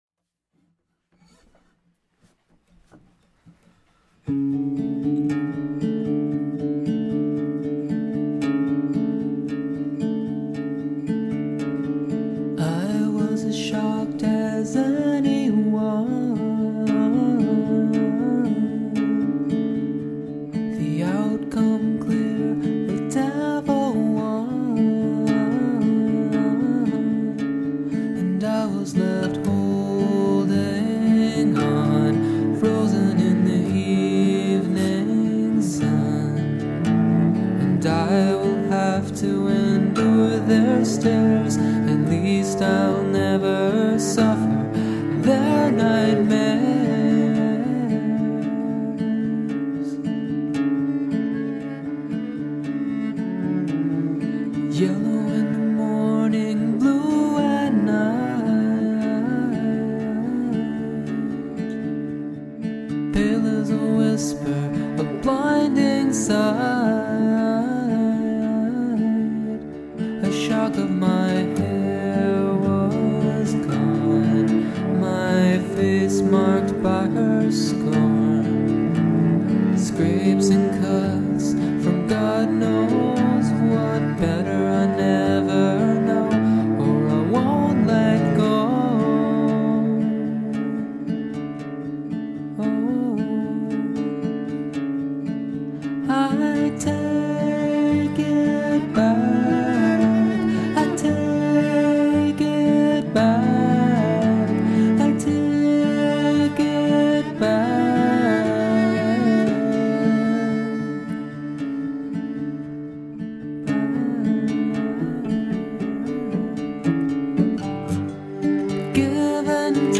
Drop D tuning
finger picking (1232)
Great cello part!